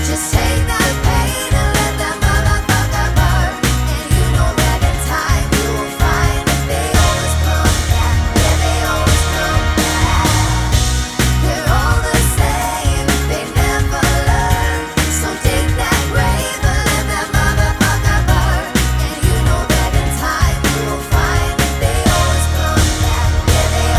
explicit Pop (2010s) 3:22 Buy £1.50